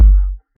Slow_It_Down_Kick(1).wav